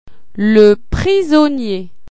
Le prisonnier   nay-uhk toH